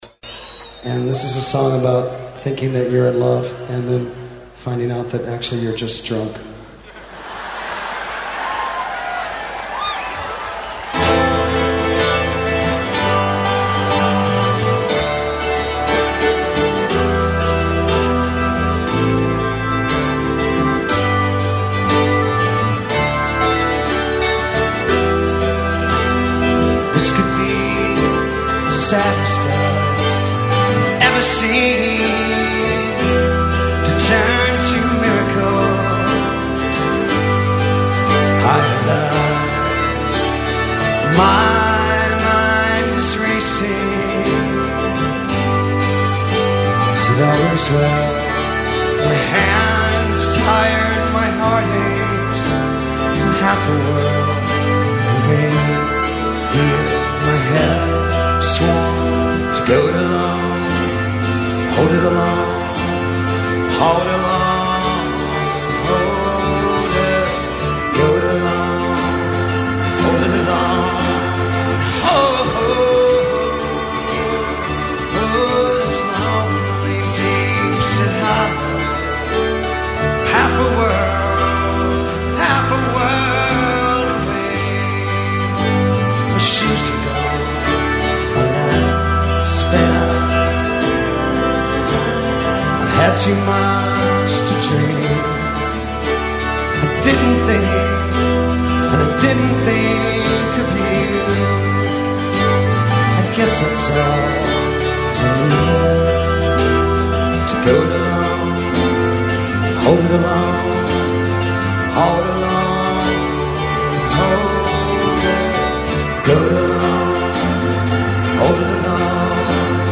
live
performance outdoors in the English countryside